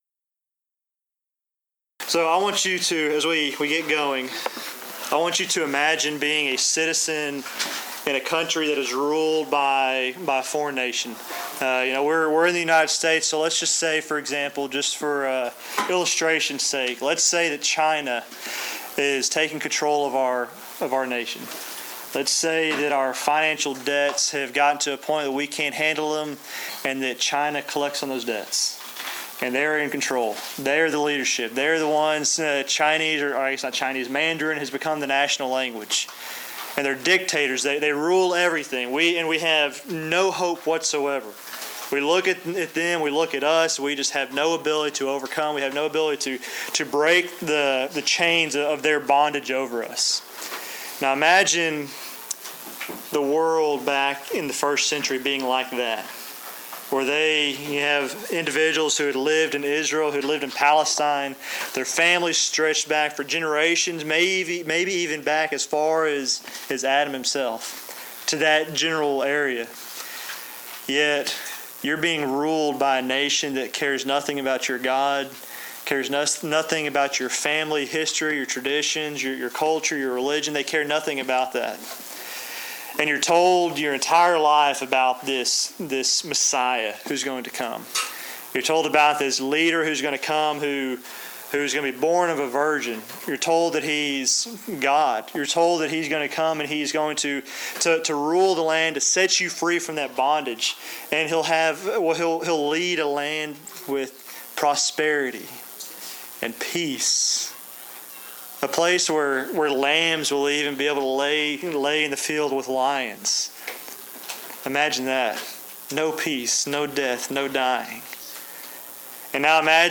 Event: Discipleship U 2016
lecture